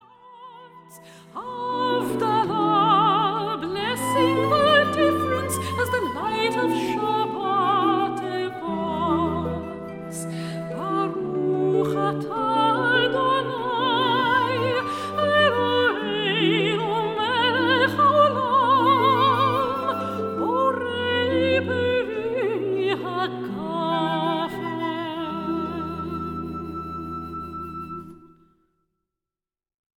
instruments, and chorus.